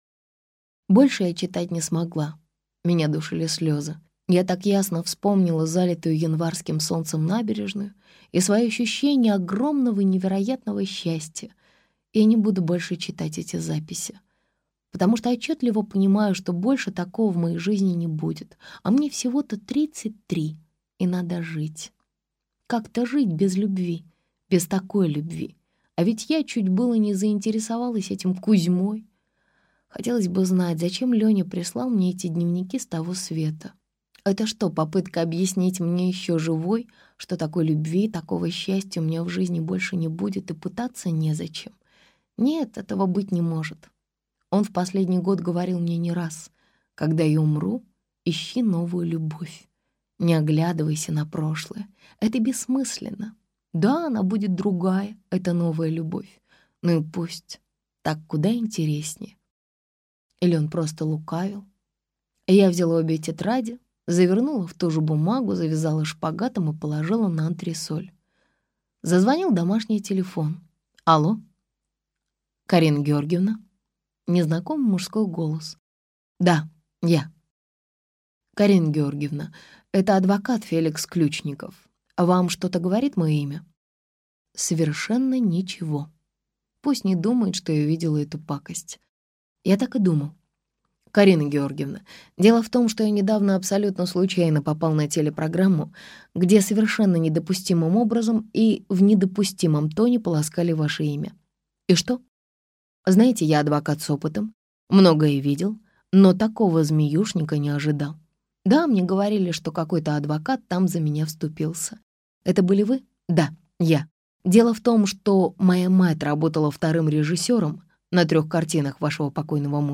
Аудиокнига А я дура пятая! - купить, скачать и слушать онлайн | КнигоПоиск